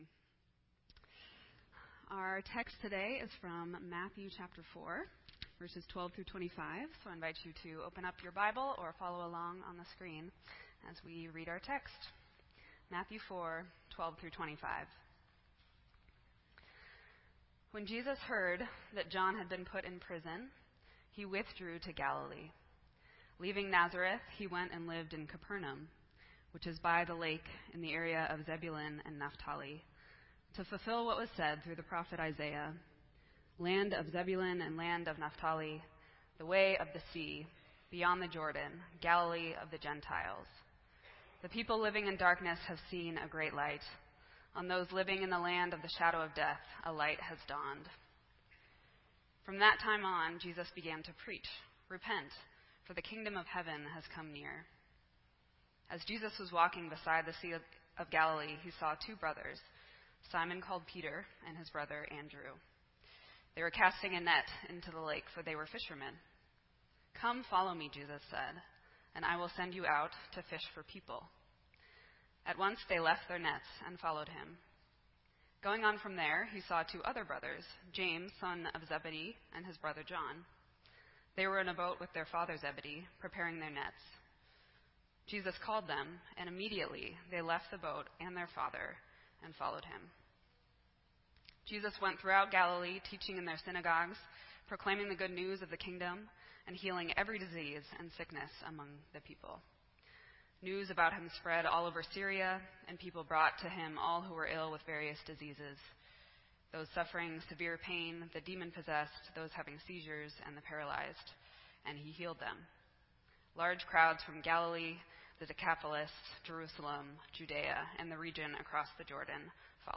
This entry was posted in Sermon Audio on January 23